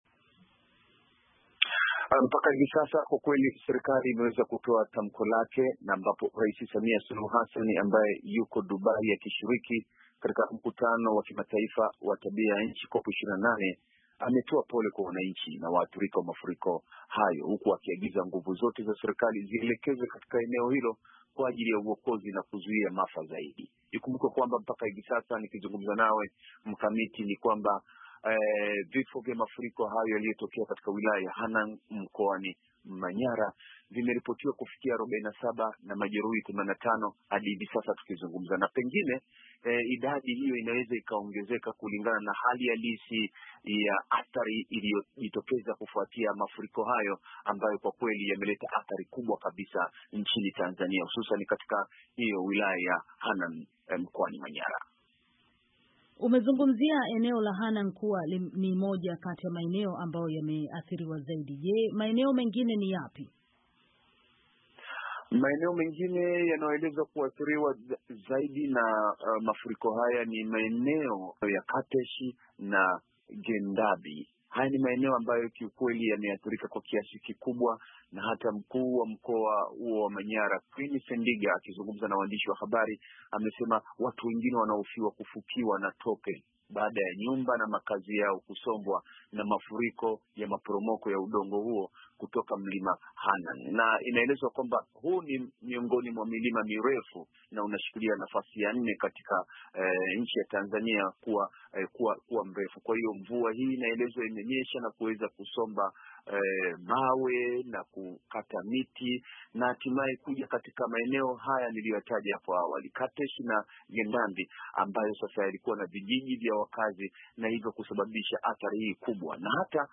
VOA imezungumza na mwandishi wa kujitegemea